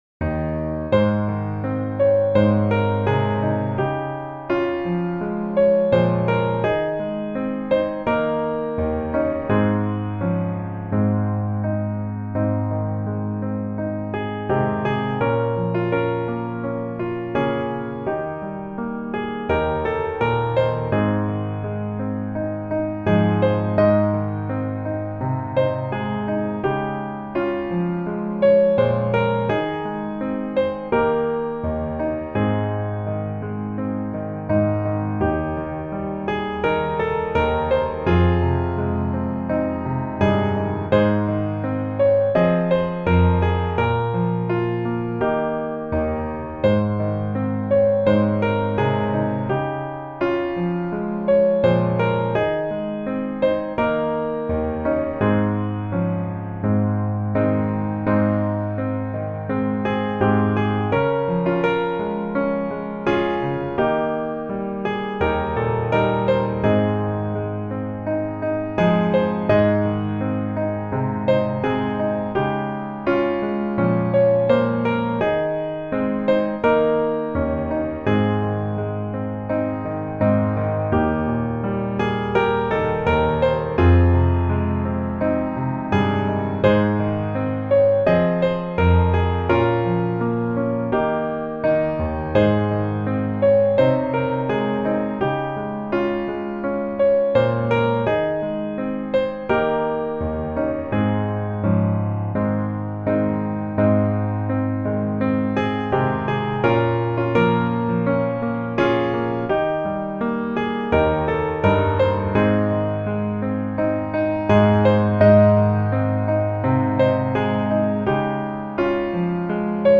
Ab Majeur